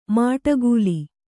♪ māṭaguli